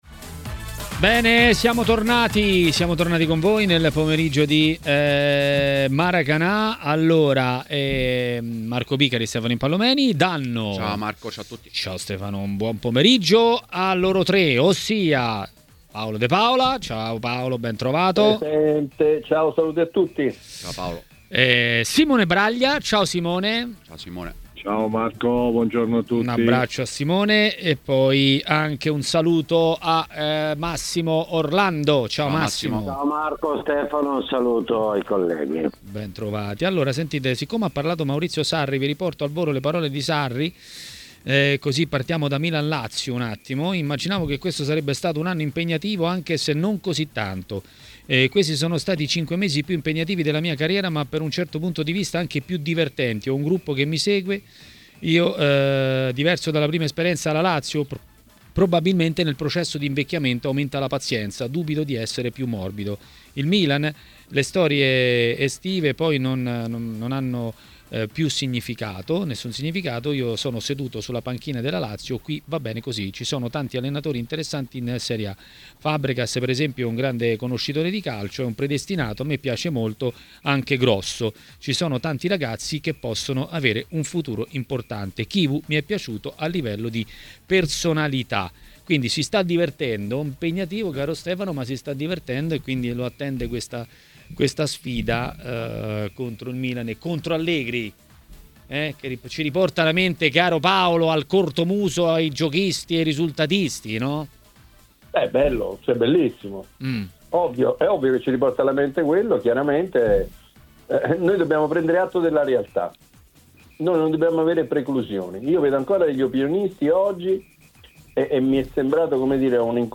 L'ex calciatore Massimo Orlando ha parlato dei temi di giornata a TMW Radio, durante Maracanà.